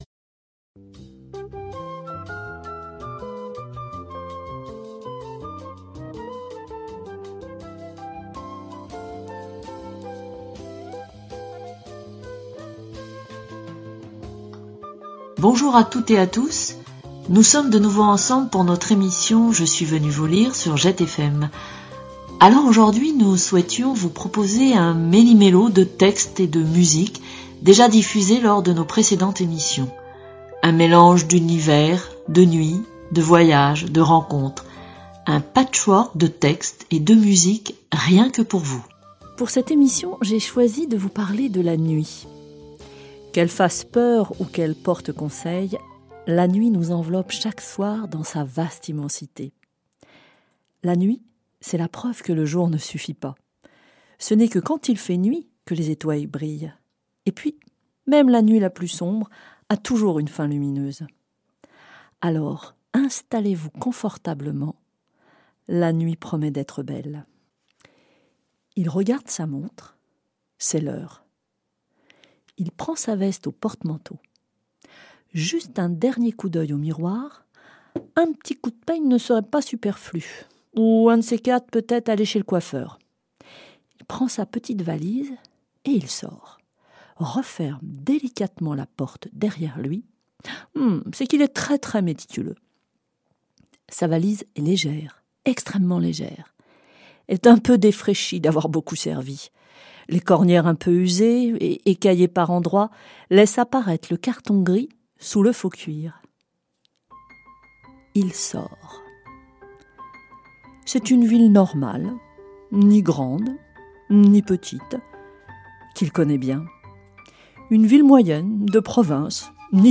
Nos studios n'étant pas encore accessibles et quelques impératifs nous empêchant d'enregistrer chez nous avec nos moyens du bord, nous vous offrons un florilège (best of en bon français) de nos émissions passées.
Pour pimenter la rediffusion, nous avons changer quelques illustrations musicales.